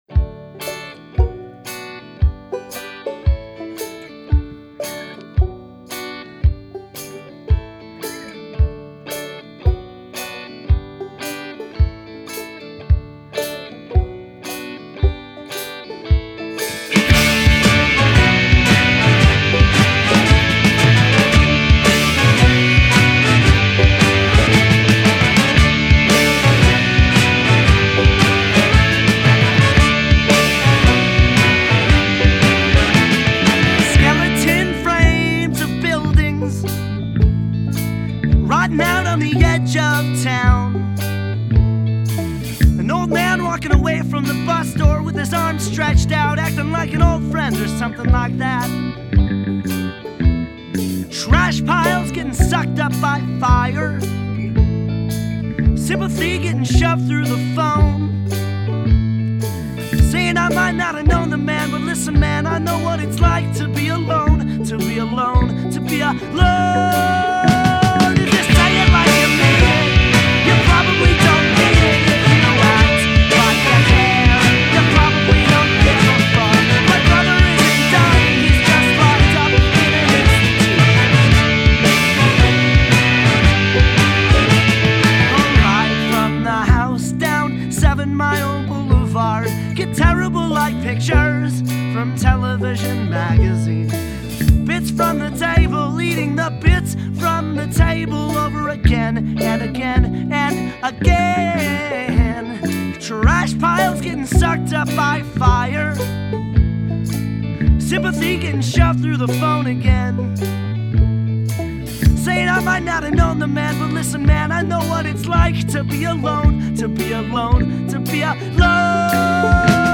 Percussion
bass, mandolin
trumpet
saxophones
banjo, piano
trombone